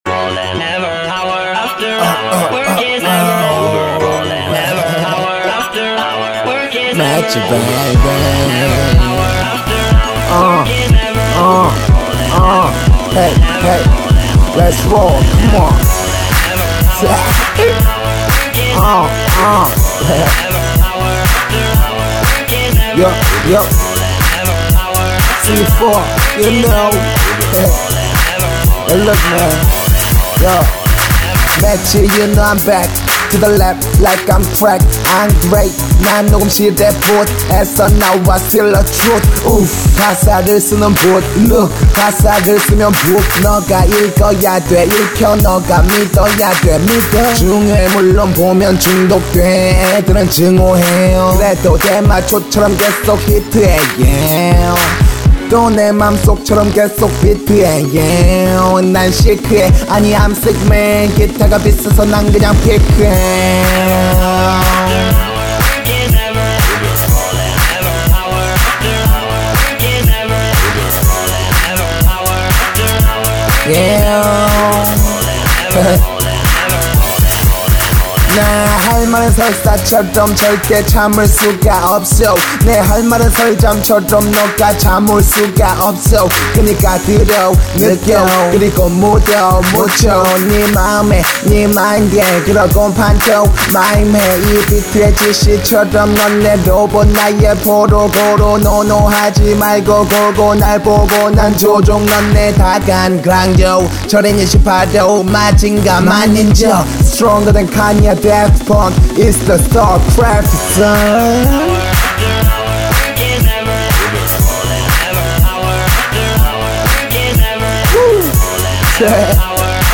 • [국내 / REMIX.]
그냥 랩입니다.